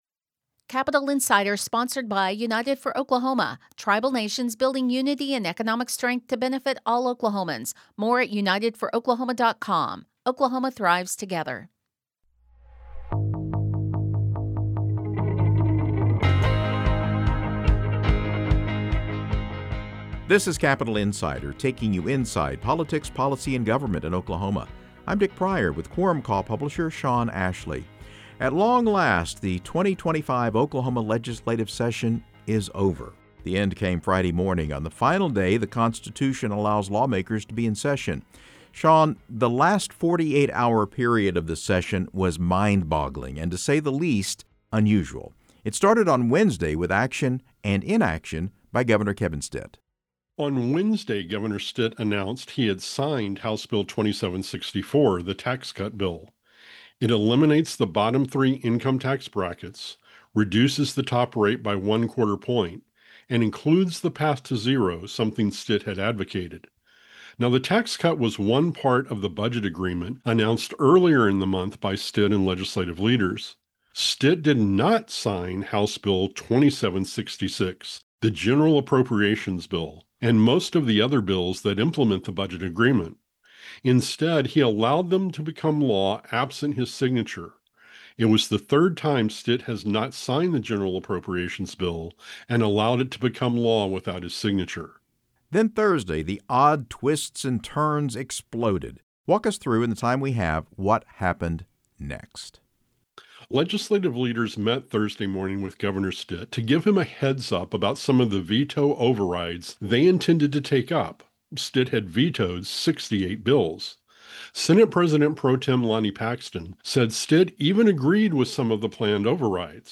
plus interviews with newsmakers.